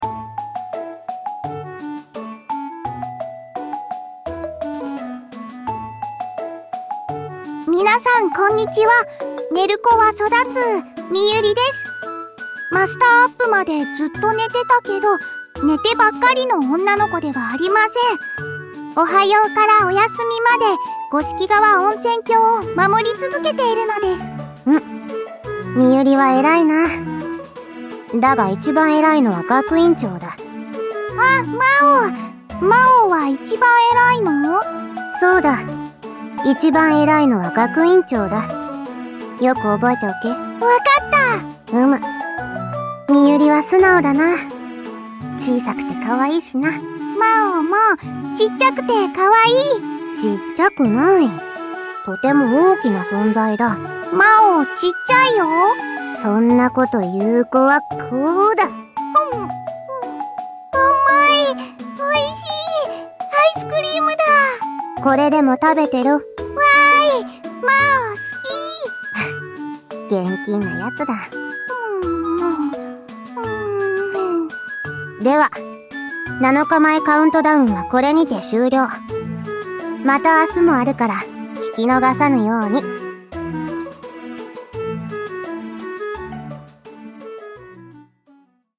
発売七日前カウントダウンボイス公開！